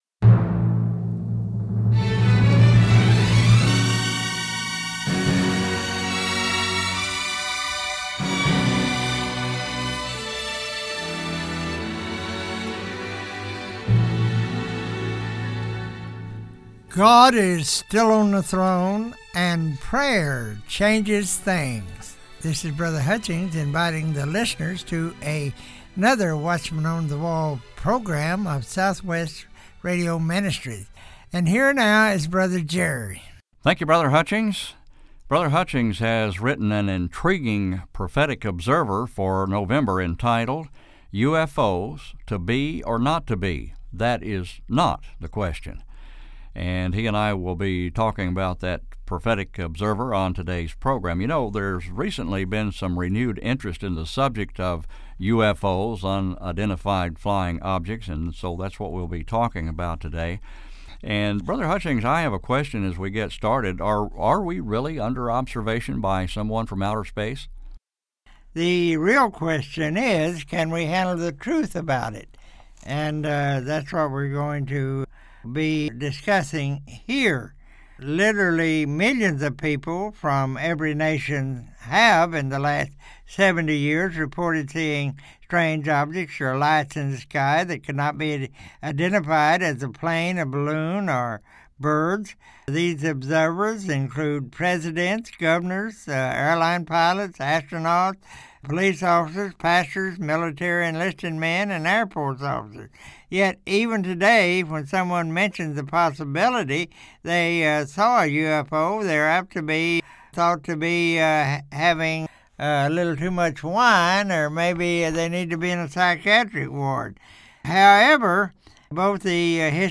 Audio Interview with the Author